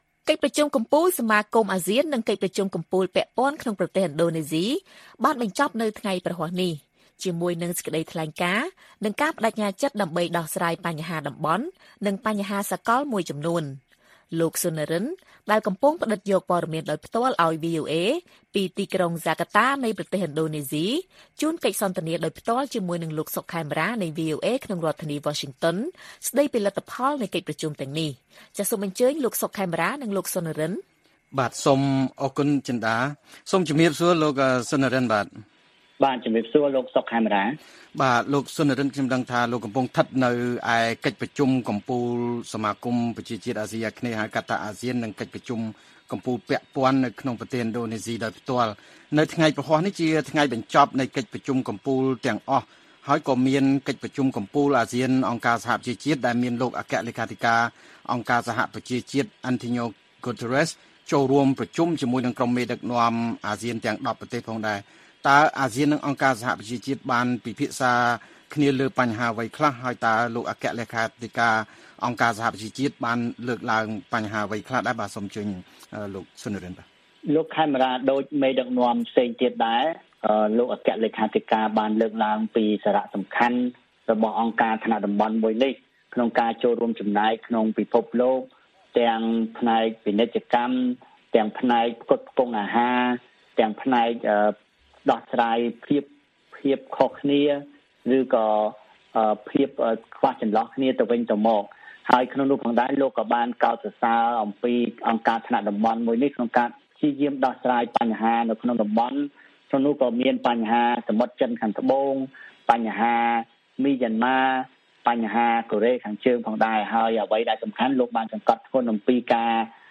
បទសន្ទនា VOA៖ លទ្ធលផនៃកិច្ចប្រជុំកំពូលអាស៊ាននៅទីក្រុងហ្សាការតាប្រទេសឥណ្ឌូណេស៊ី